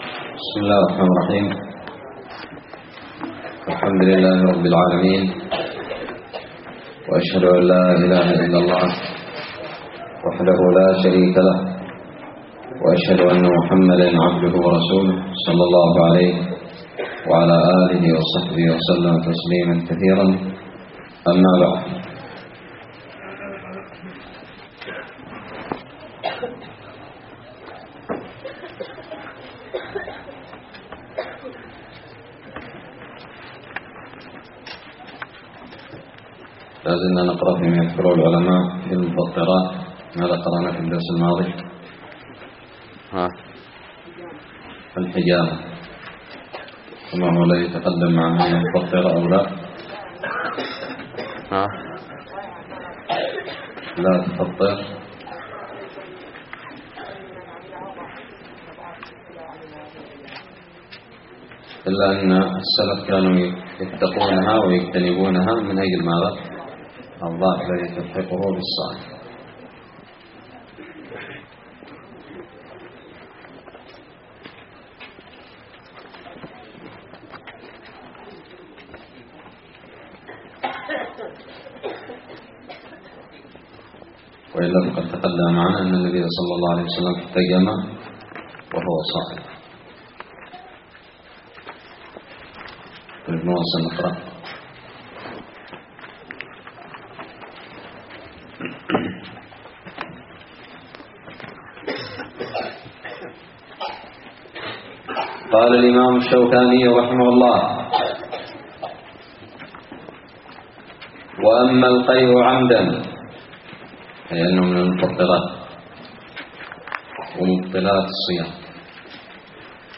الدرس الثامن عشر من كتاب الصيام من الدراري
ألقيت بدار الحديث السلفية للعلوم الشرعية بالضالع